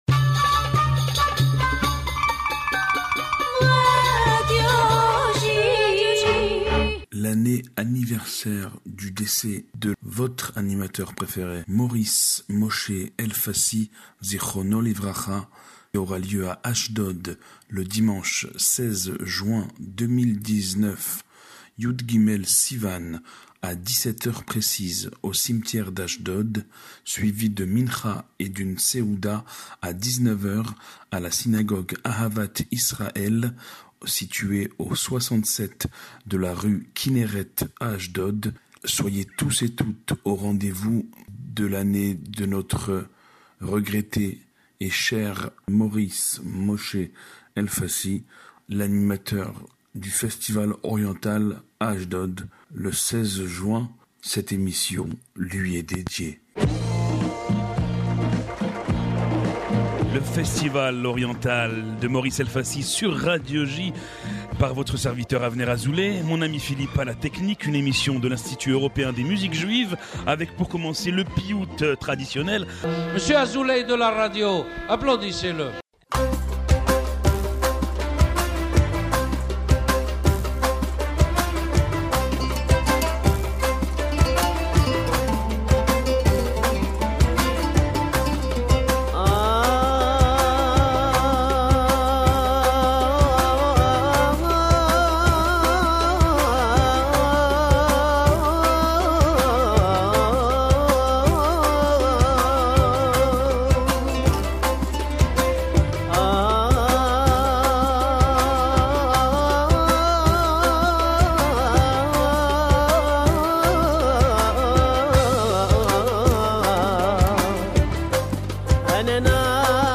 « Le festival oriental » est une émission de l’Institut Européen des Musiques Juives entièrement dédiée à la musique orientale.